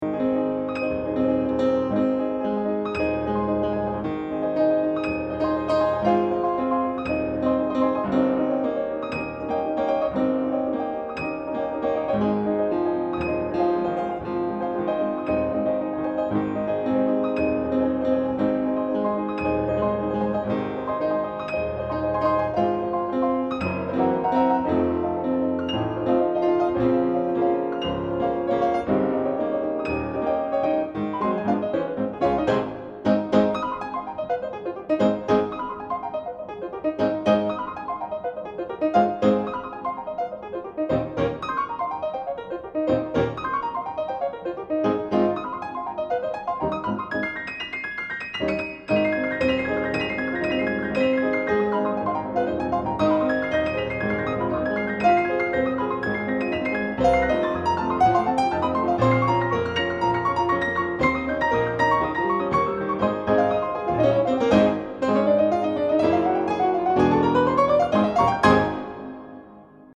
Delightful Jazz Tunes for Two Pianos
The superb engineering also helps.